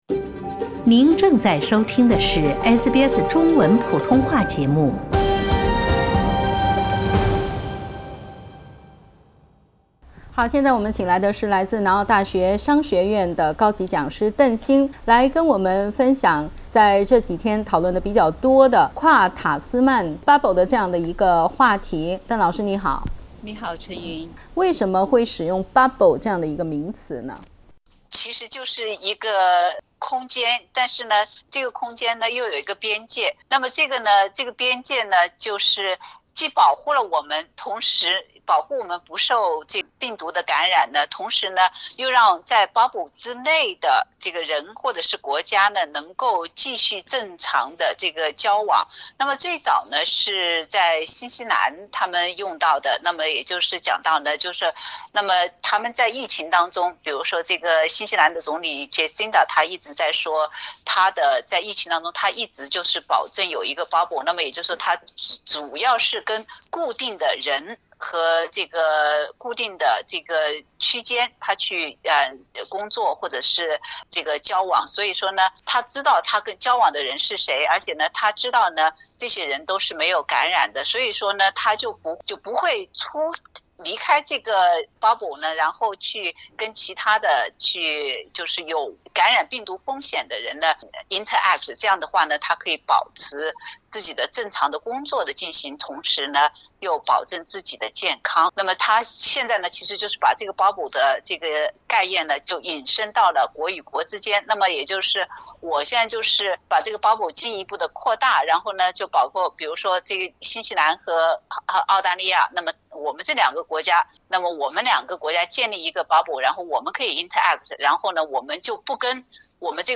澳新领导人正在讨论一个被称为“跨塔斯曼免疫泡泡区”（trans-Tasman quarantine bubble）的计划，以期让澳新之间的旅游和交往回复正常。 点击图片收听详细报道。